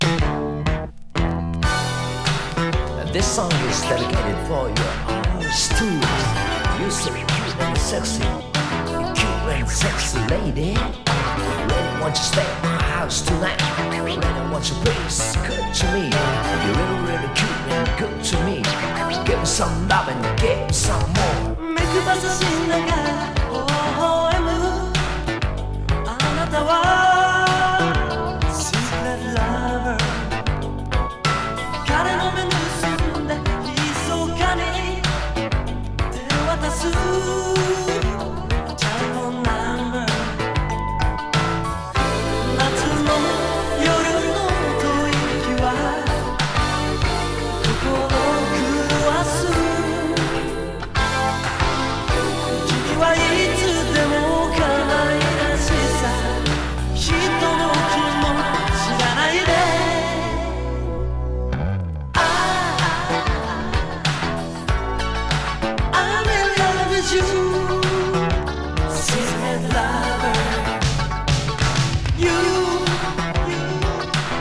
少したどたどしいヴォーカルが魅力のキラキラ・ポップ！